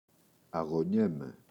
αγωνιέμαι [aγoꞋɲeme]